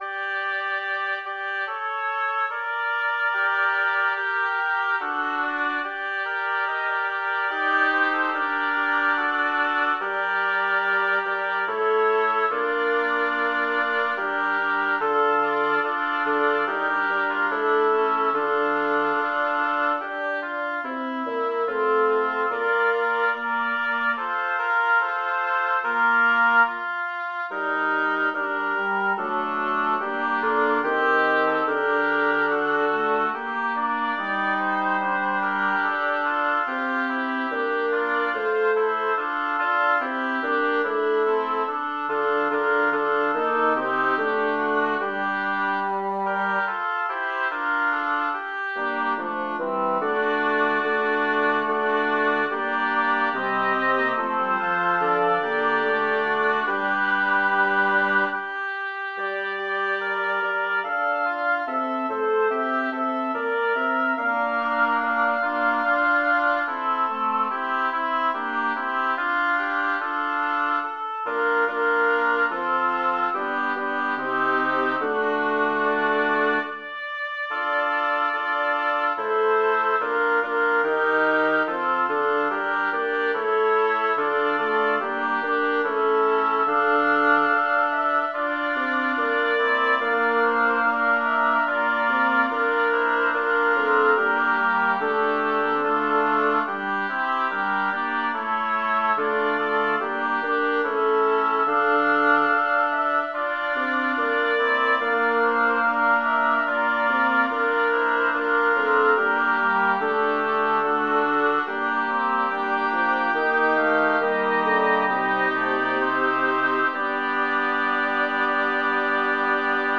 Title: Amami, vita mia Composer: Andrea Gabrieli Lyricist: Number of voices: 5vv Voicing: SSAAT Genre: Secular, Madrigal
Language: Italian Instruments: A cappella